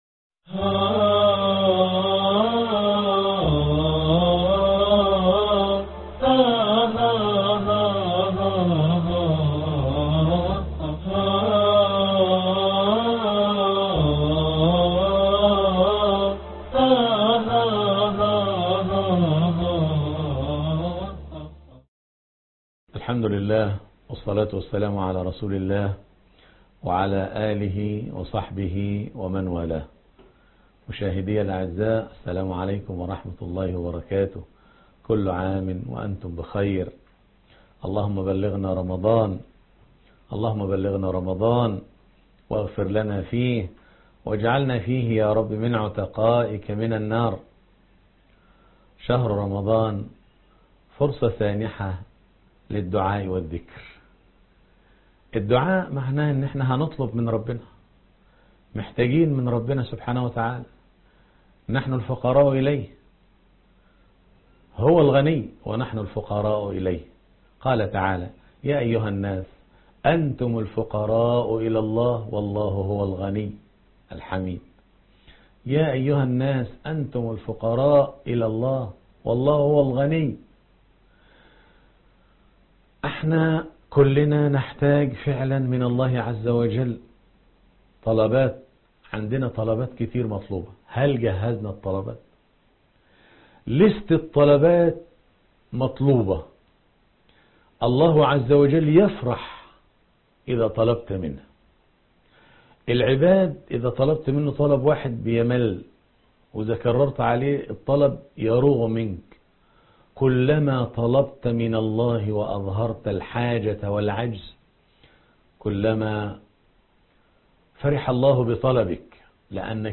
الدرس 25 ( الدعاء ) يا باغي الخير أقبل